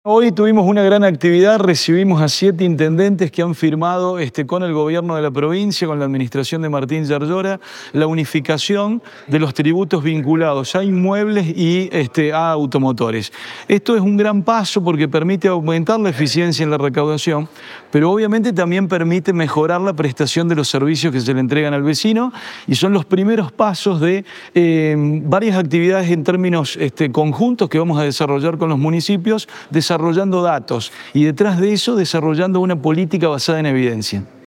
Guillermo Acosta – ministro de Economía y Gestión Pública